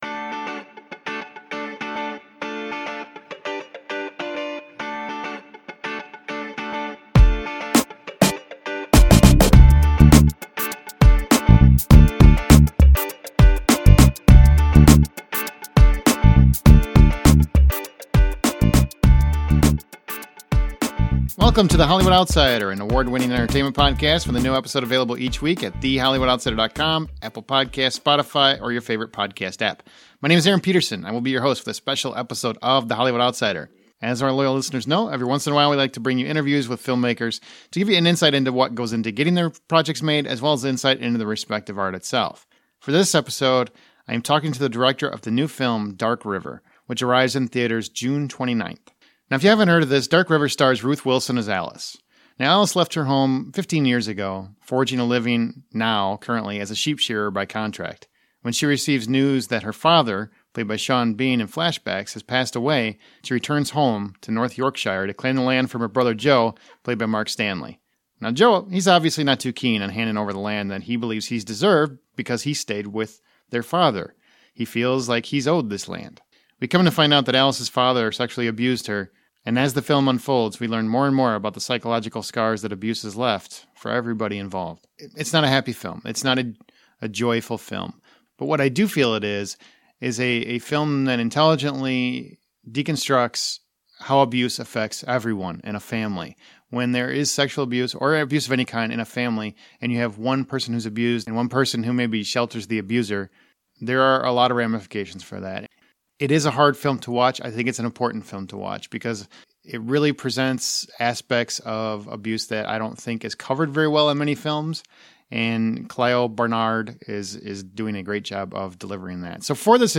Dark River | Interview with Director Clio Barnard